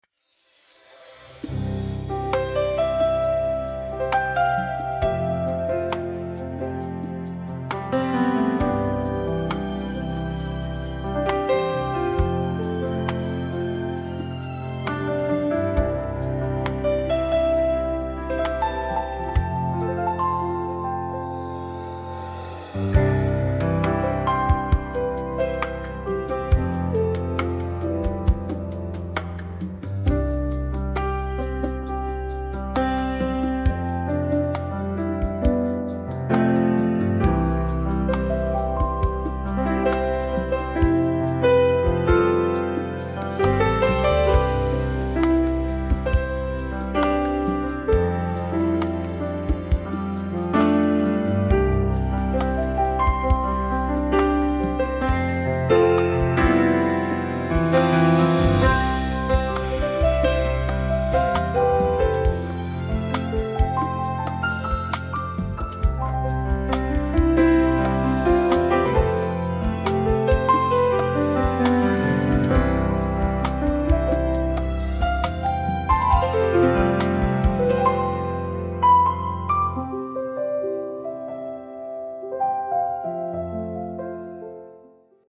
Stay tuned for more prayerful instrumentals.